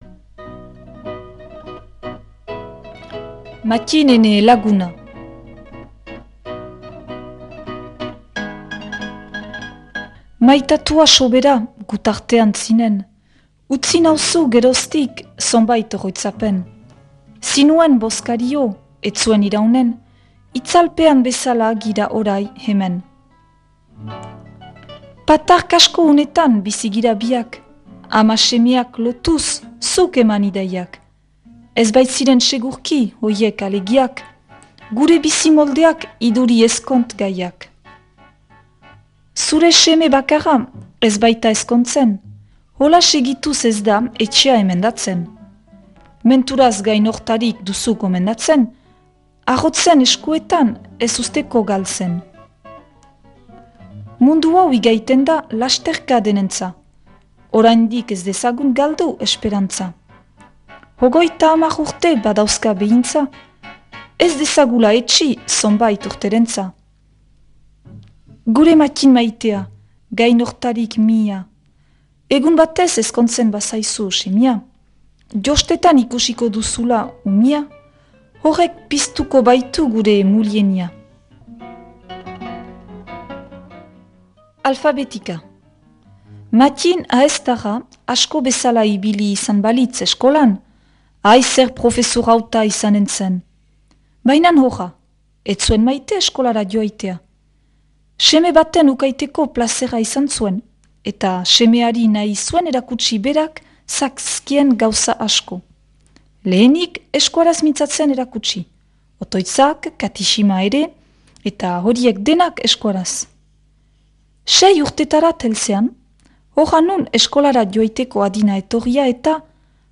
Gure artxiboetarik atera sail hau, Ahetzeko Mattin Trecu (1916-1981) bertsolariari eskainia. Mila bederatziehun ta laurogoi ta bian Maddi Trecu bere emazteak "Mattin, ene laguna" izenburuko liburutik hartu hogoi ta hamar atal edo zati dira.
proposatu irakurketa da.